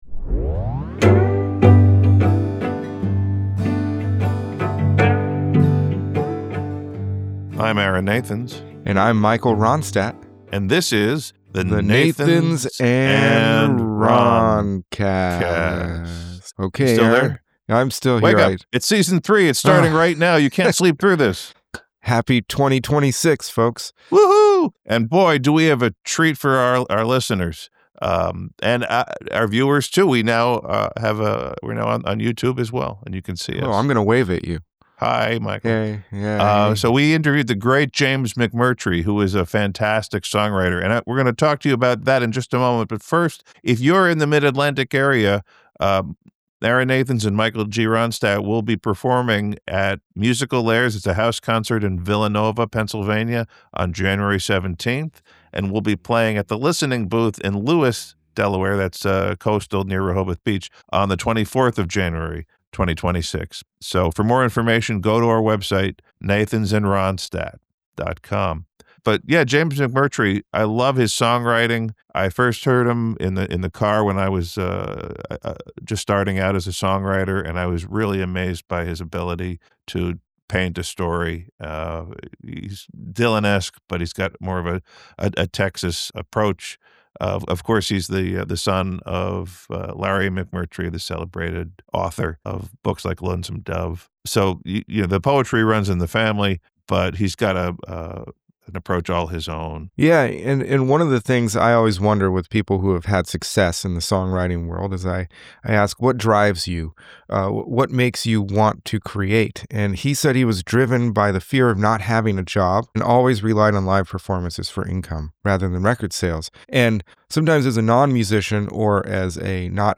For our Season 3 premiere, we are honored to present our interview with the great James McMurtry, the Texas songwriter who paints vivid portraits in song with sly and smart observations. In our conversation, we cover what motivates him to write; his memories of participating in the Kerrville Folk Festival song contest; his thoughts on international touring and his memories of Guy Clark.